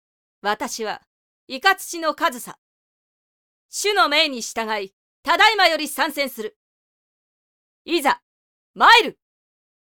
【サンプルセリフ】
ゆったりした大人の女性が良いなと思って設定させていただきました。